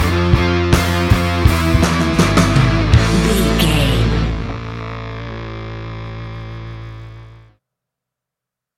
Ionian/Major
A♯
hard rock
heavy rock
distortion